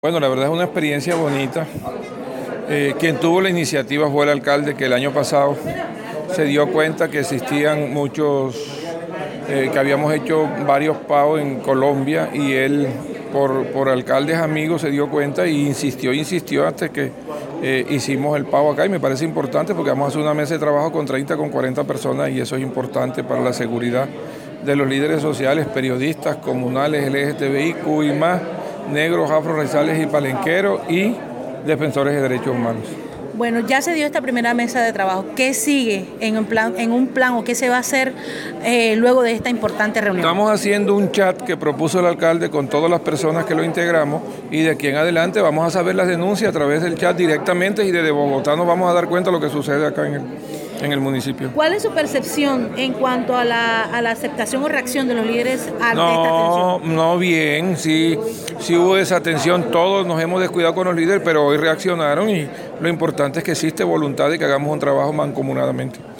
En dialogo con este medio informativo, del director del Plan, Carlos Alberto Barriga, manifestó estar satisfecho con esta primera mesa de trabajo, y se espera continuar por el ismo derrotero par aa protección de los lideres, lideresas y población minoritaria.